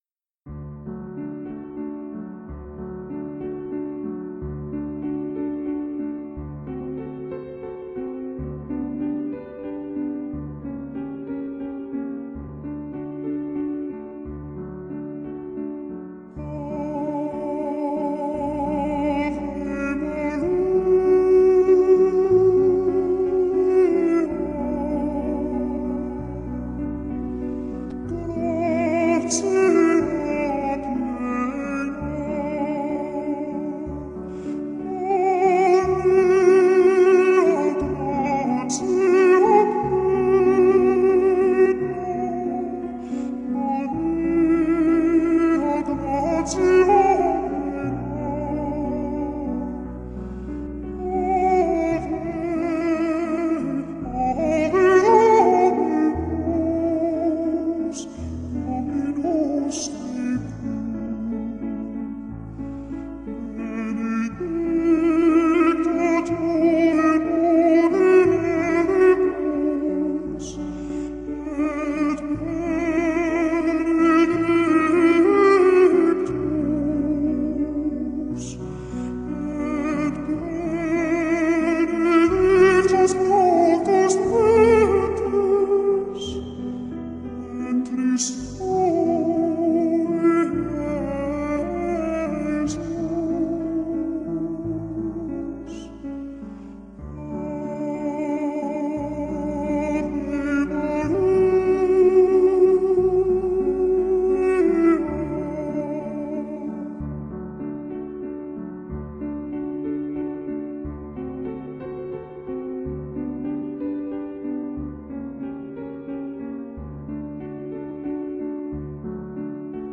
Voicing: Solo Song